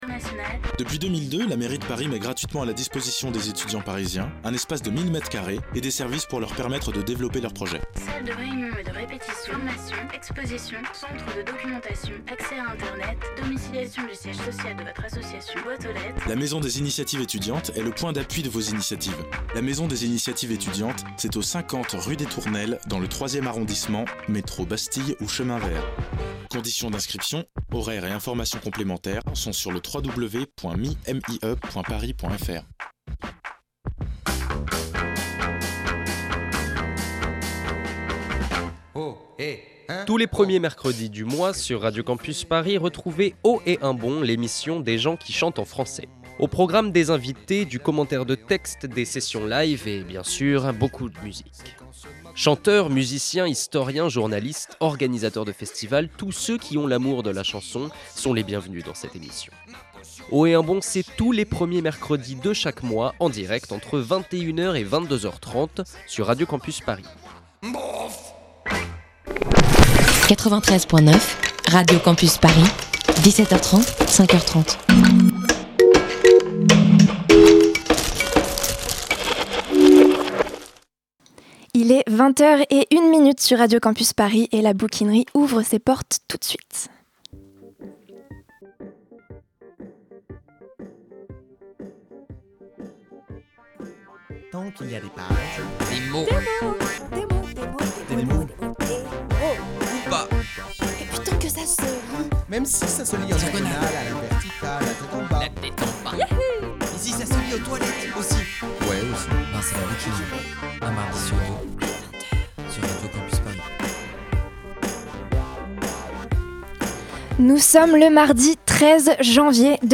Partager Type Entretien Culture mardi 13 janvier 2015 Lire Pause Télécharger Ouvre-là !
On a choisi nos armes : Au programme, des lectures, autour du thème des Grandes Gueules.